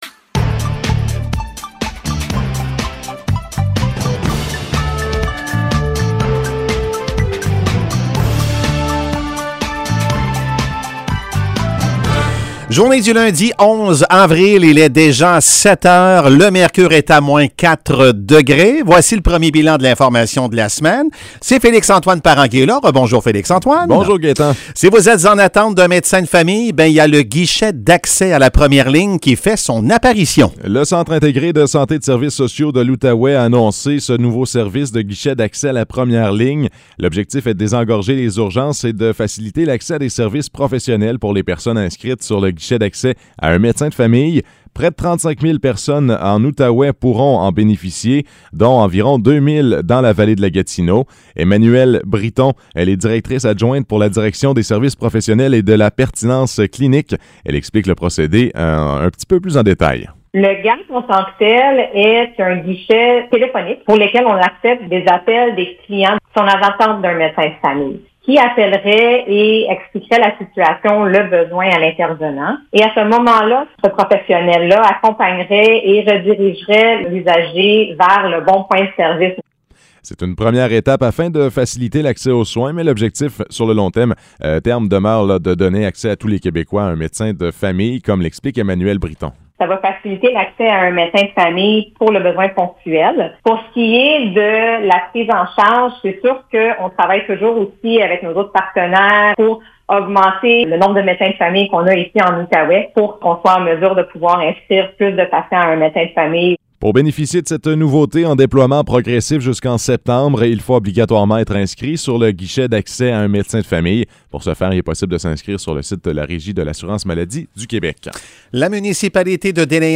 Nouvelles locales - 11 avril 2022 - 7 h